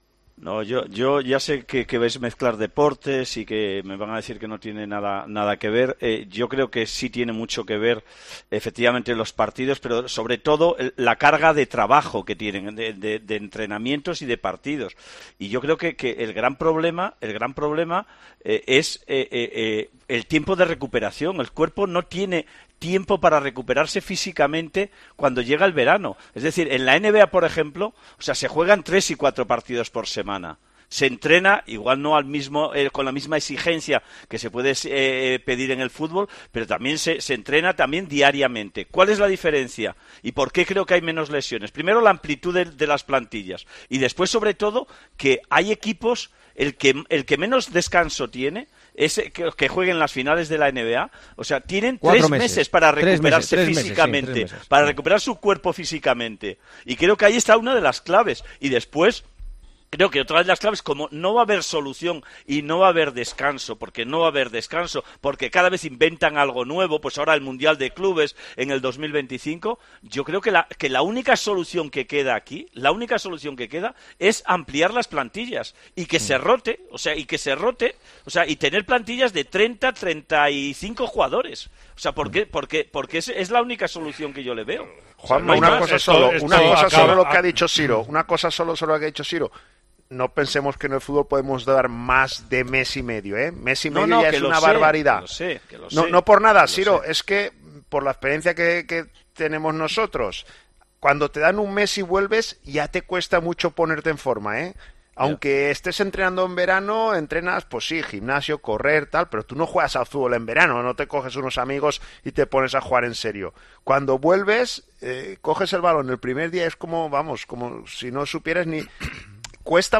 Juanma Castaño lanzó la pregunta sobre el saturado calendario de fútbol a todos los tertulianos del Tertulión de los domingos.
La opinión de los contertulios de el Tertulión de Tiempo de Juego por los parones de selecciones